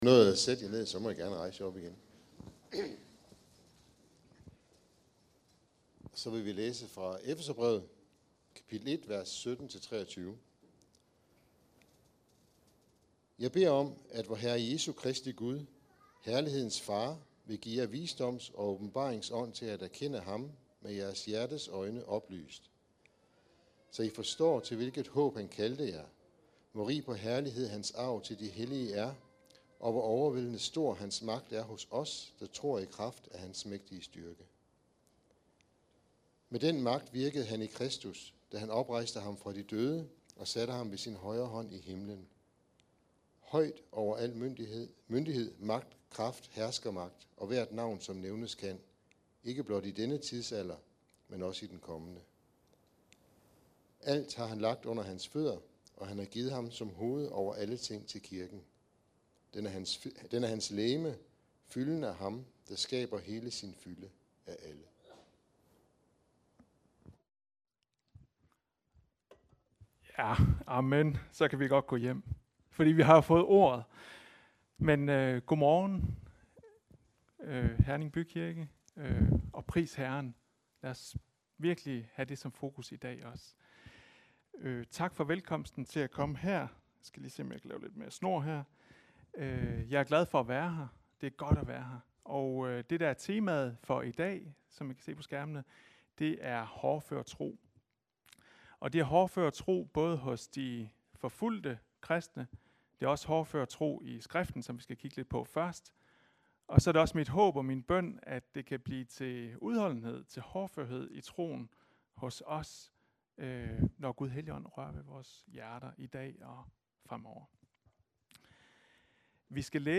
Prædiken med besøg fra OpenDoors